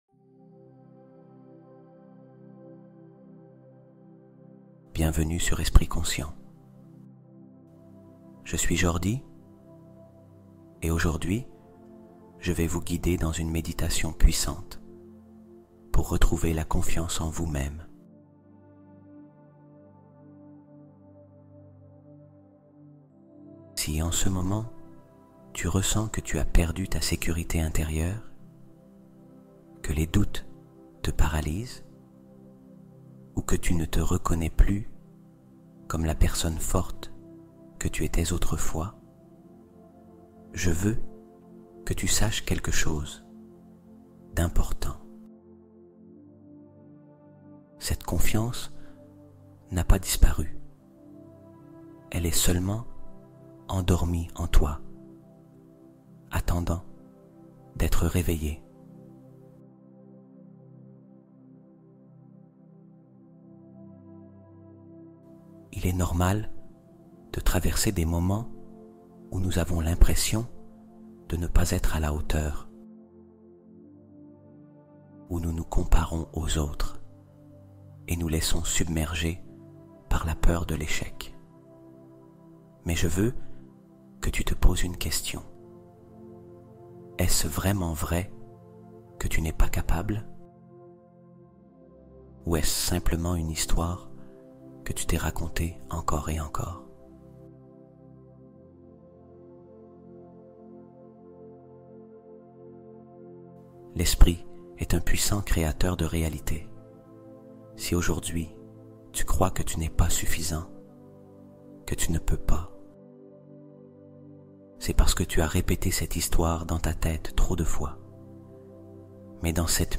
Auto-hypnose pour voyager dans l’astral cette nuit